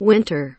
winter kelimesinin anlamı, resimli anlatımı ve sesli okunuşu